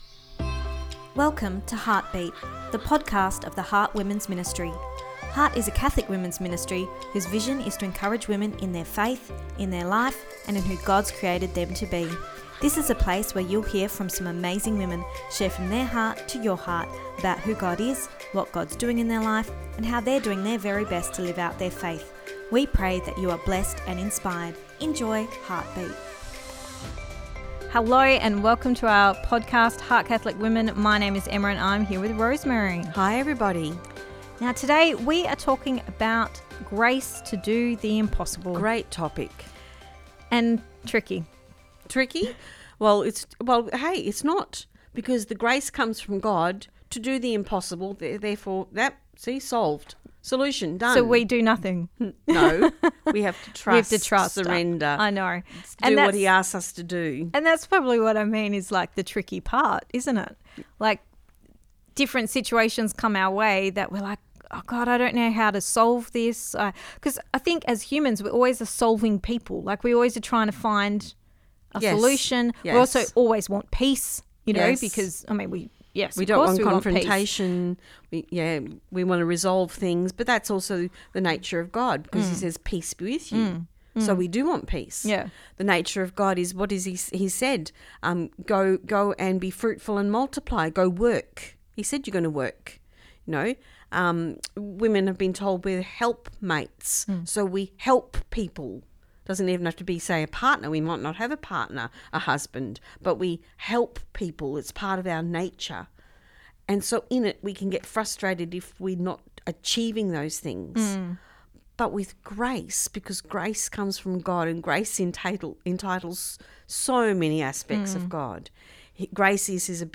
Ep283 Pt2 (Our Chat) – Grace to do the Impossible